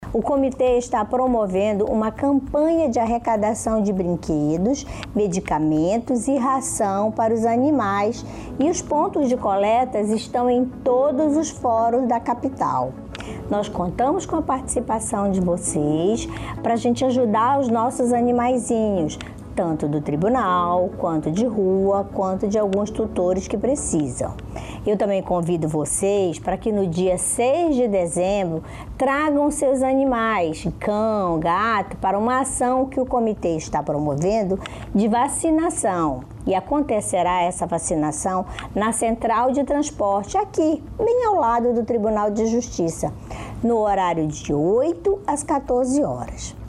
SONORA-JUIZA-.mp3